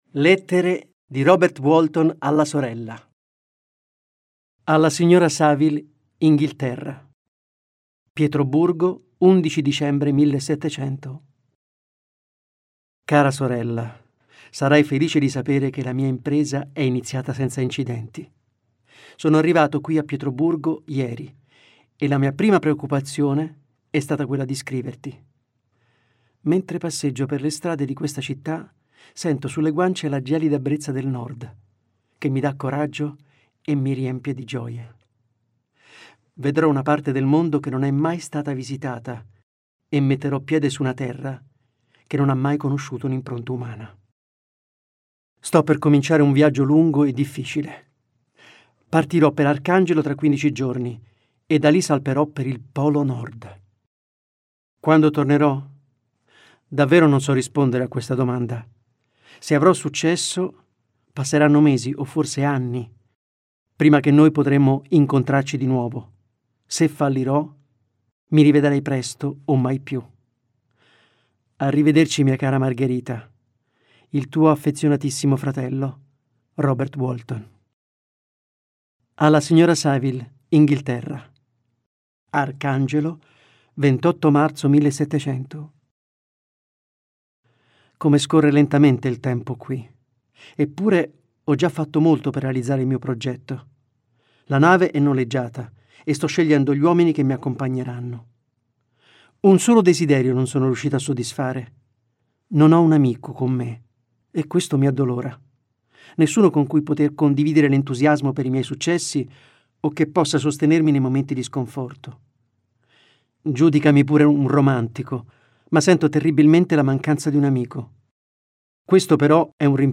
Letto da: Giulio Scarpati